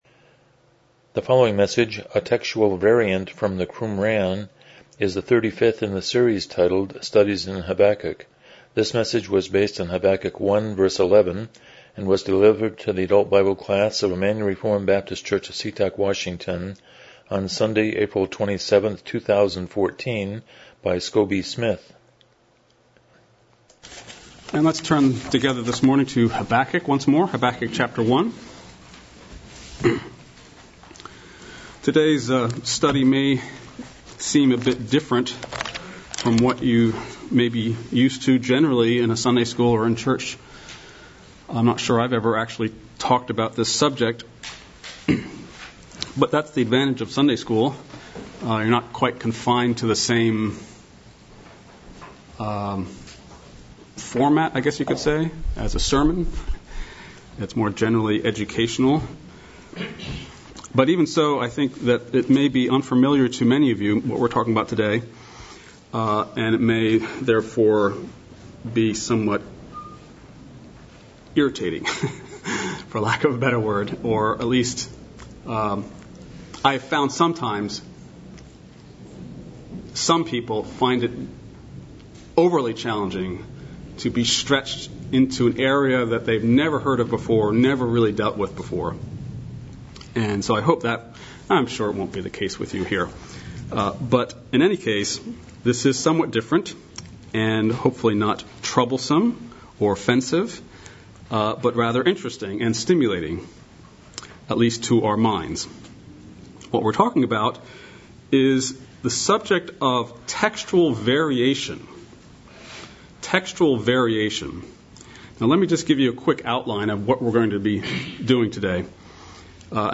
Series: Studies in Habakkuk Passage: Habakkuk 1:11 Service Type: Sunday School « 19 How Does the New Testament Describe the Christian Life?